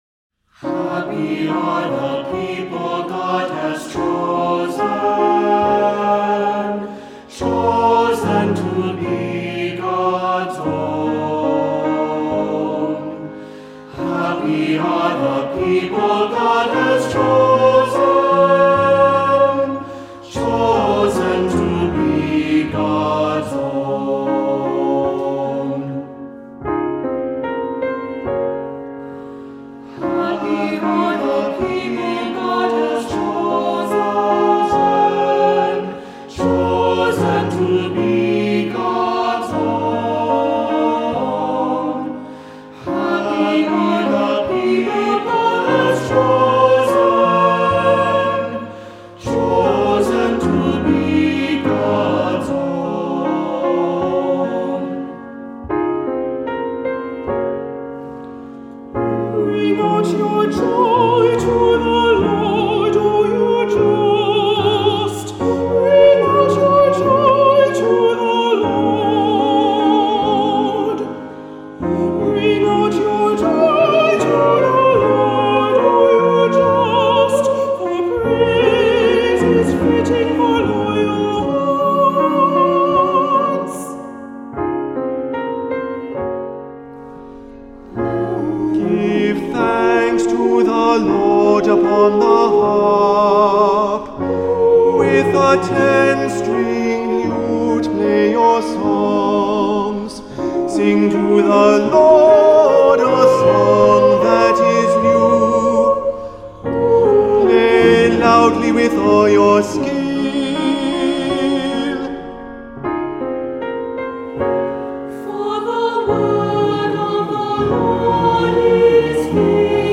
Voicing: Two-part mixed; Cantor; Assembly